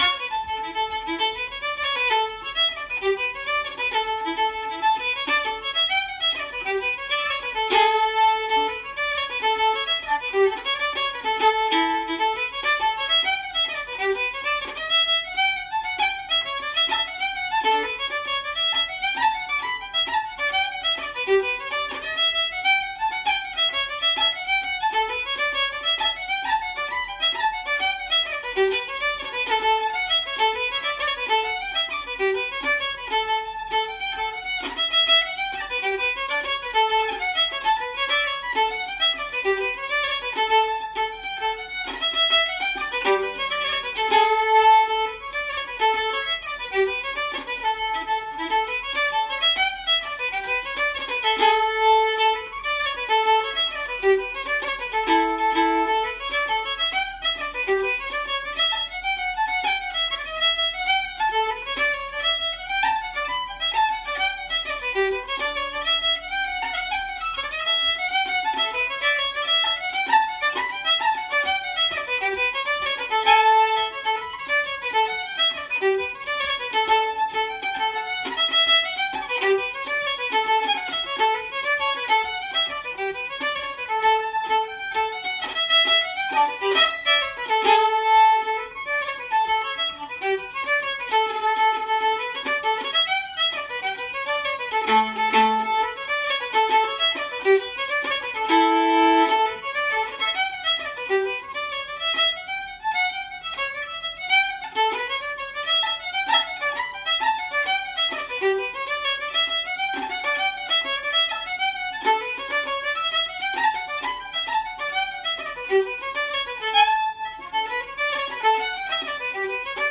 Mooncoin Jig set